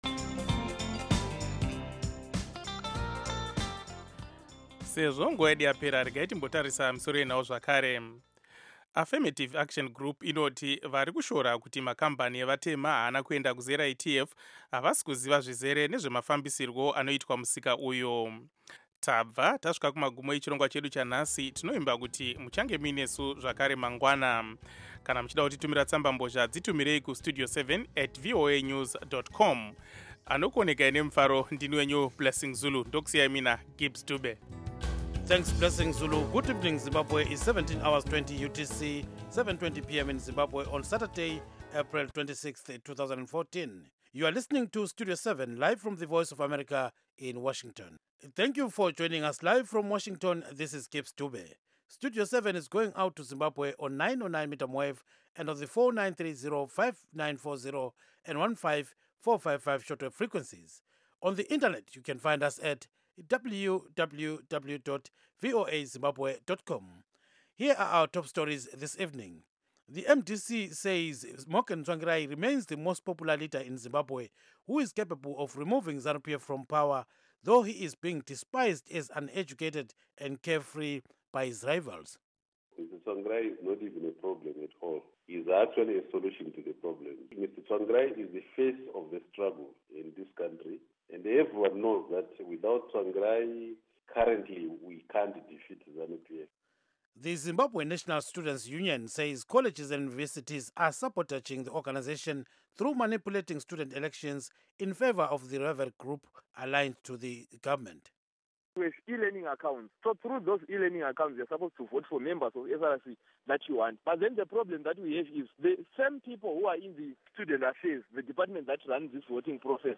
Studio 7 for Zimbabwe provides comprehensive and reliable radio news seven days a week on AM, shortwave and satellite Schedule: Monday-Friday, 7:00-9:00 p.m., Saturday-Sunday, 7:00-8:00 p.m., on Intelsat 10 repeats M-F 9-11 p.m. Local Time: 7-9 p.m. UTC Time: 1700-1900 Duration: Weekdays: 2 hours; Weekends: 1 hour Listen: MP3